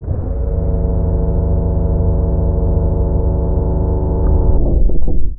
gear.wav